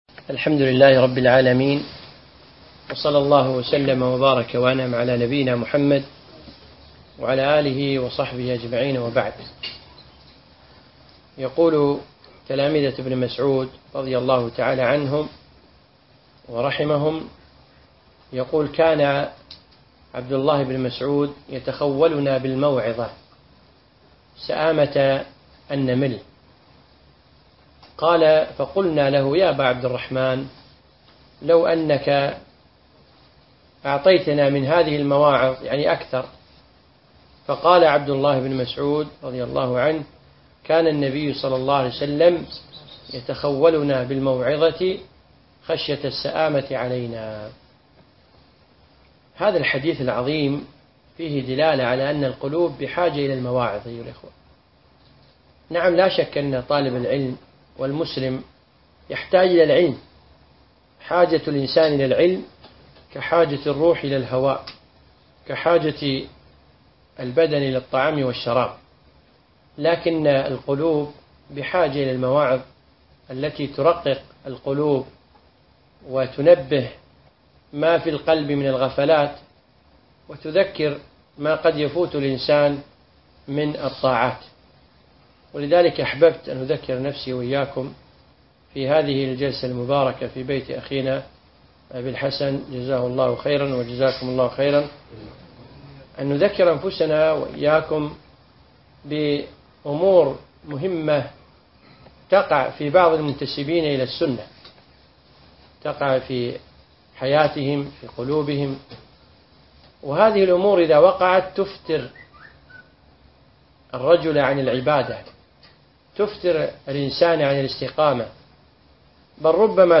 موعظة: هل تحس بالفتور ؟